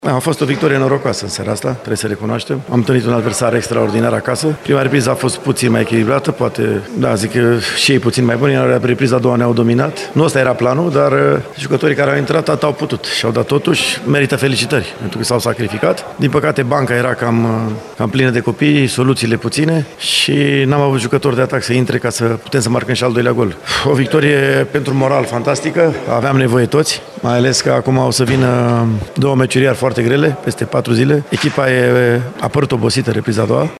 Antrenorul CFR-ului, Dan Petrescu, admite faptul că victoria echipei sale a fost norocoasă: